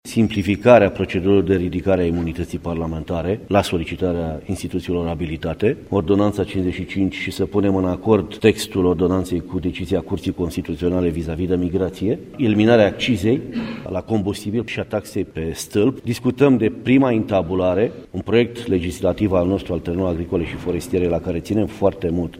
Deputatul PNL Tinel Gheorghe, după întâlnirea grupurilor parlamentare ale liberalilor de azi: